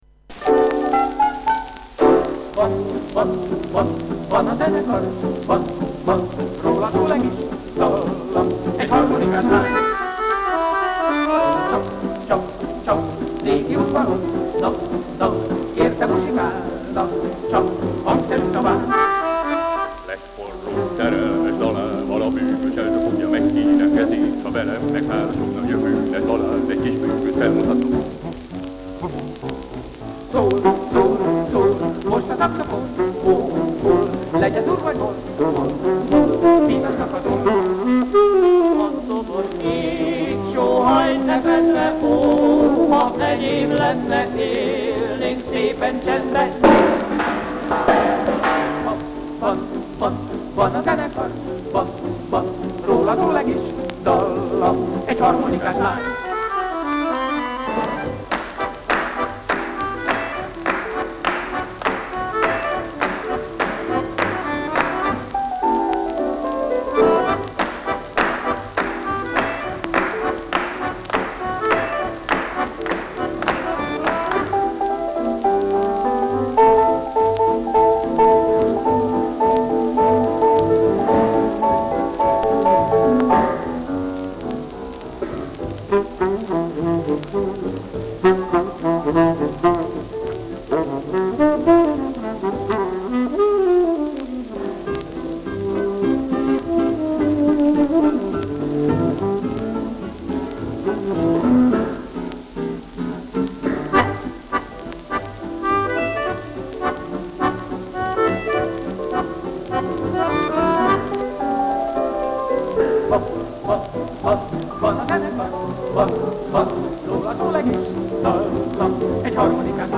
78-rpm
Натуральный звук
• tango